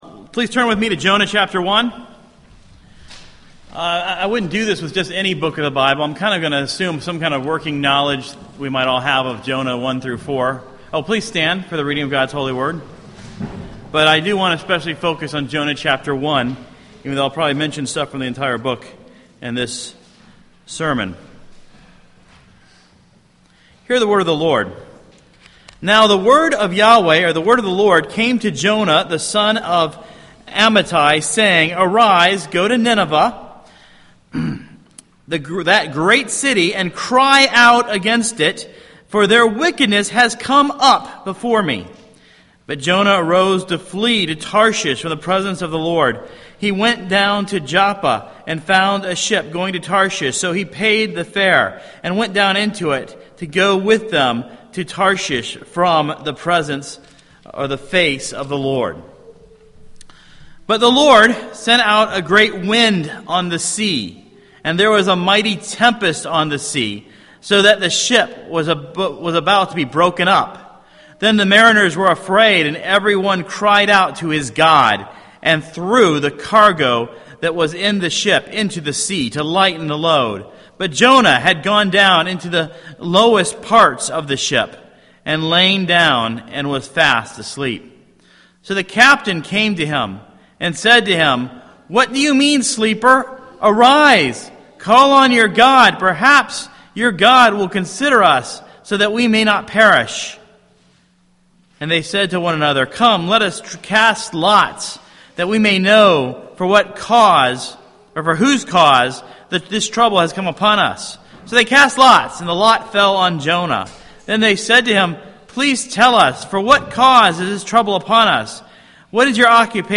Sermons Feb 28 2014 Preached Feb. 23